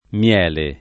mL$le] s. m. — ant. o poet. mele [m$le] (forma domin. dalle origini all’800): Mele e locuste furon le vivande [m$le e llok2Ste f2ron le viv#nde] (Dante); tutto unto di mele [t2tto 2nto di m$le] (Boccaccio); Più che mel dolci, d’eloquenza i fiumi [pL2 kke mmHl d1l©i, d elokU$nZa i fL2mi] (Tasso); Stillano mele i tronchi [St&llano m$le i tr1jki] (Manzoni); come volano le mosche al mele [k1me vv1lano le m1Ske al m$le] (Leopardi); anche con acc. scr.: aveva bocca a riso, e lingua di mèle [av%va b1kka a rr&So, e ll&jgUa di m$le] (De Sanctis); il favo del mèle nella bocca del leone [il f#vo del m$le nella b1kka del le1ne] (Carducci) — cfr. succiamele